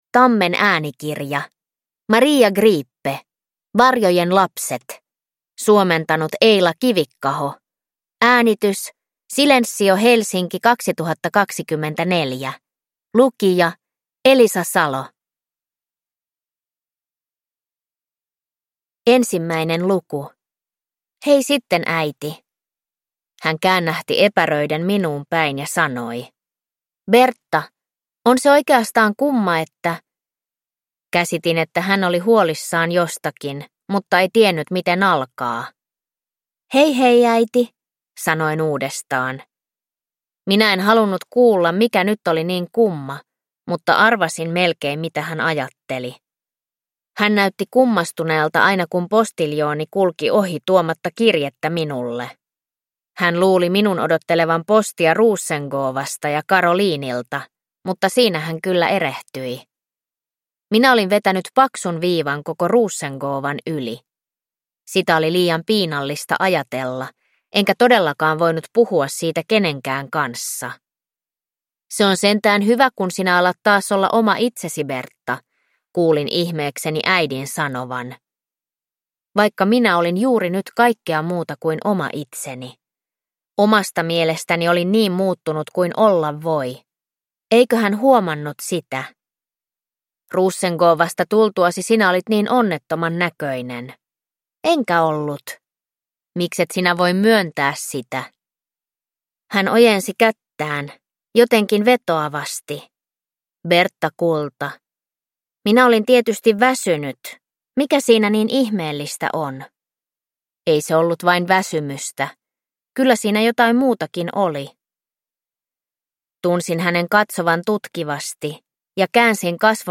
Varjojen lapset – Ljudbok